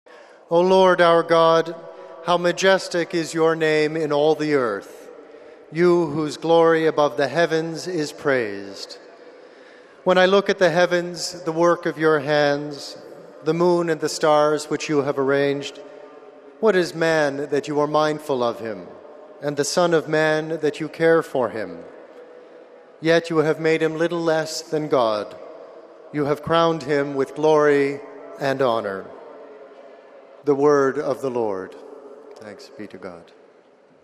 May 21, 2014 – Pope Francis on Wednesday held his weekly general audience in the Vatican’s St. Peter’s Square.
It began with aides reading a passage from the Book of Psalms.